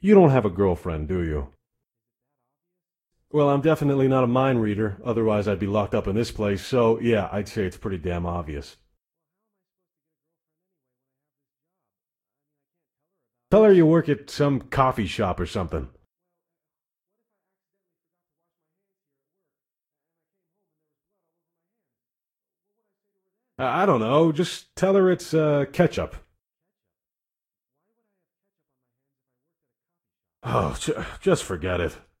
Conversation4a.ogg